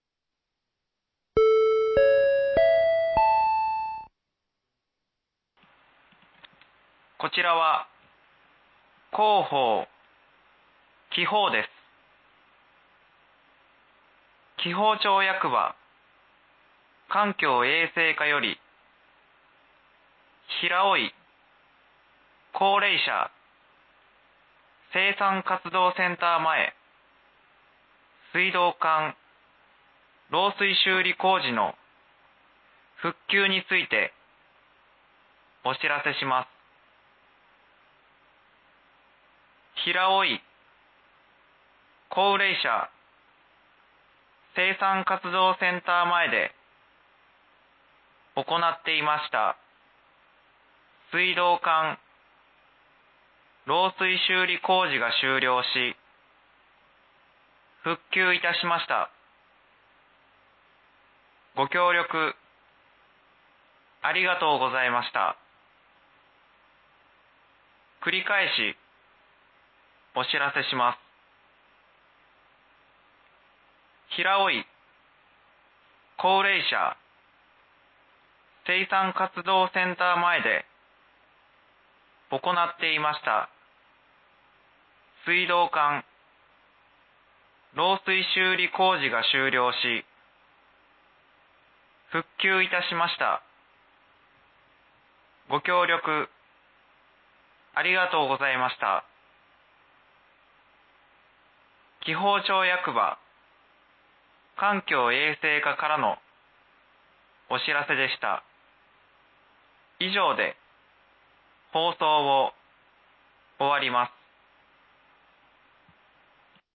（平尾井地区のみ放送）
放送音声